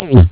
Worms speechbanks
oops.wav